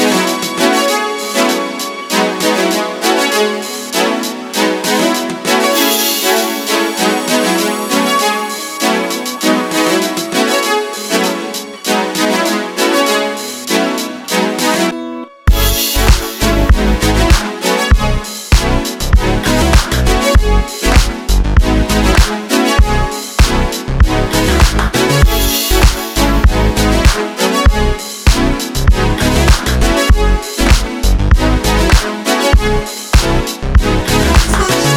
Electronic Dance
Жанр: Танцевальные / Электроника